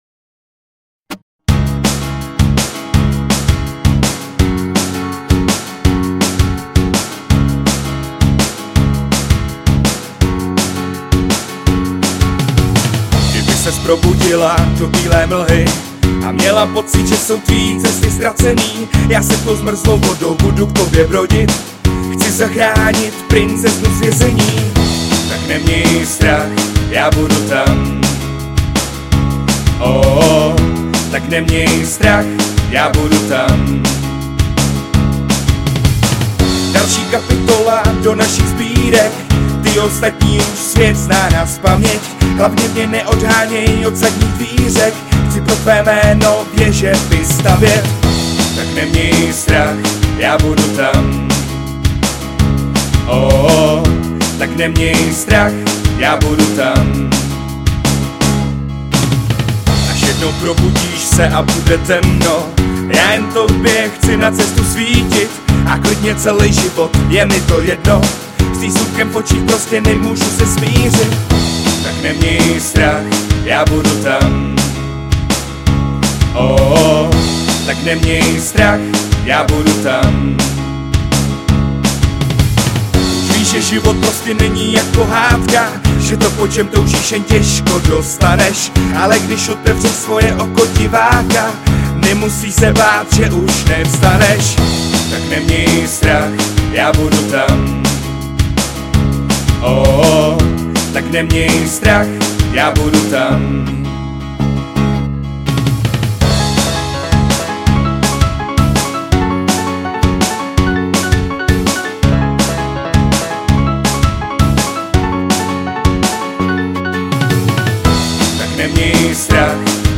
Žánr: Pop/Rock/Folk